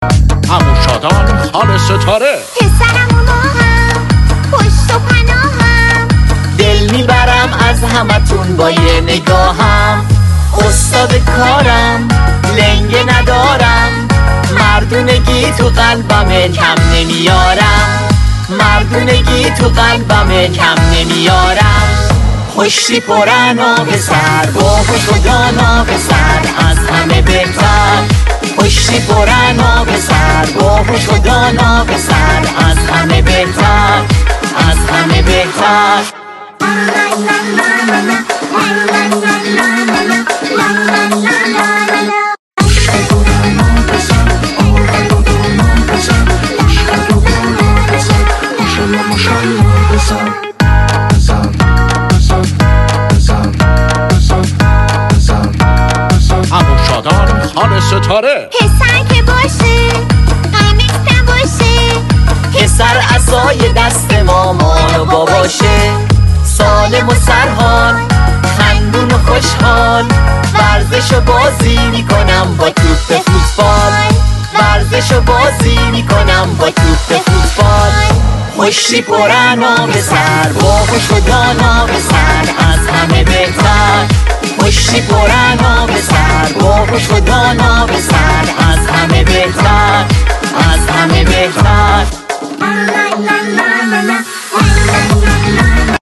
دانلود آهنگ پسرم تولدت مبارک اینستایی کودکانه